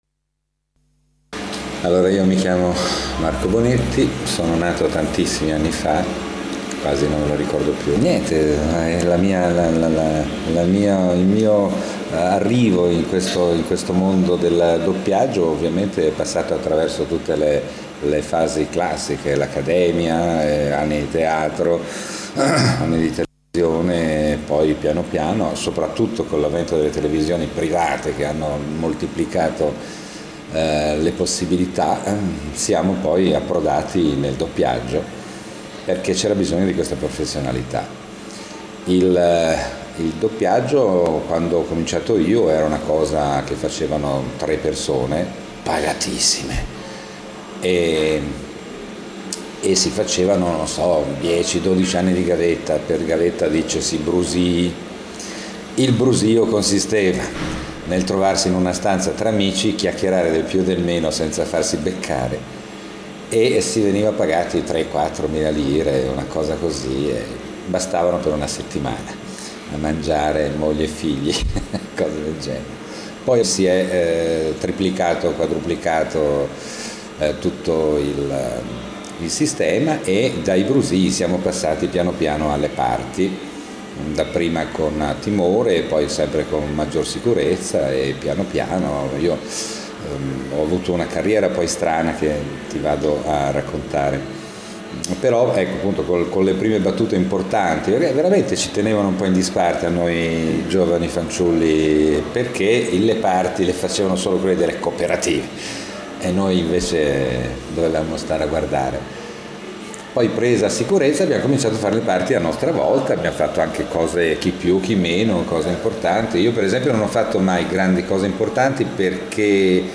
Giornata di studi della IV edizione del Premio Nazionale di Doppiaggio